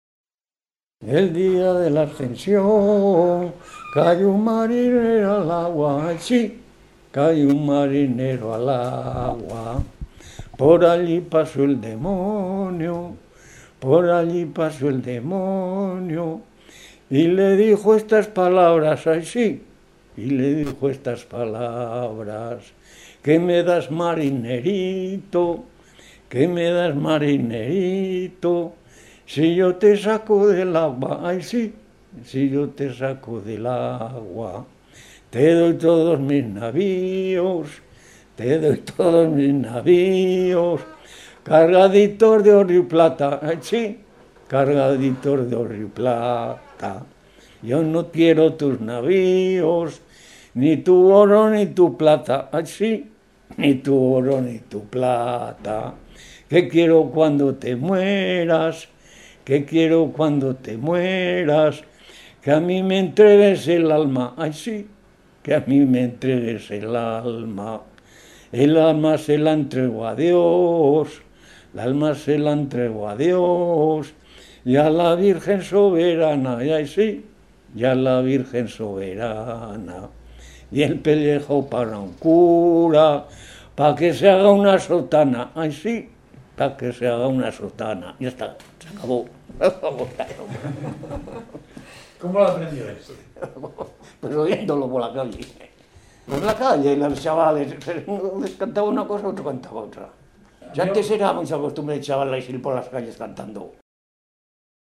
Clasificación: Romancero
Localidad: Calahorra
Lugar y fecha de recogida: Calahorra, 20 de julio de 2002